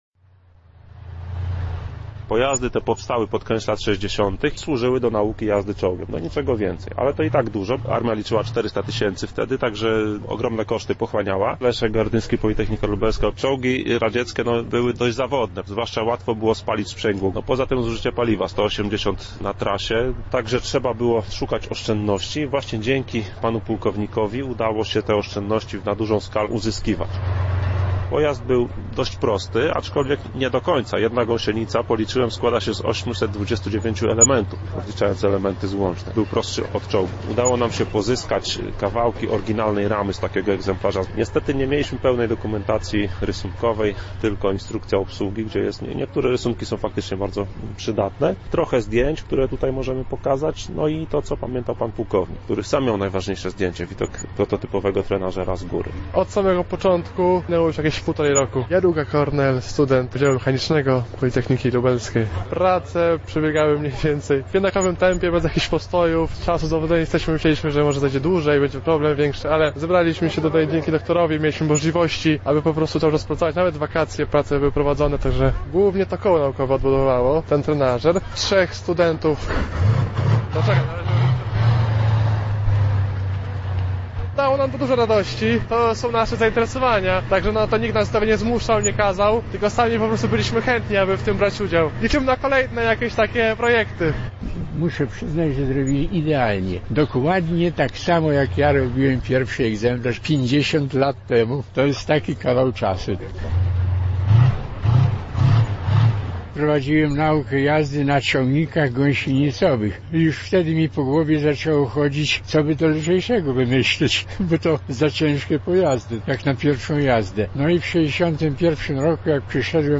Więcej usłyszycie w materiale przygotowanym przez naszego reportera, który był na premierze trenażera czołgowego na Politechnice Lubelskiej: